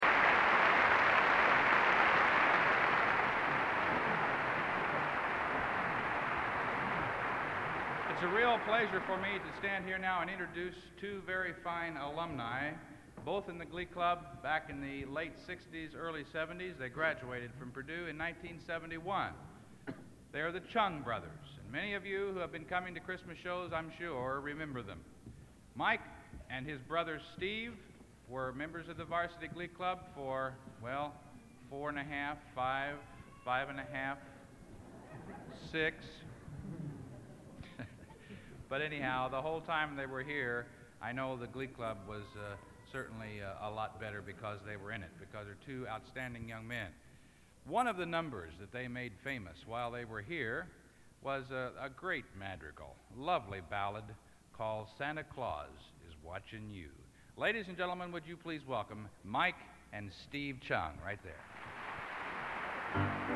Collection: Christmas Show 1983
Genre: | Type: Christmas Show |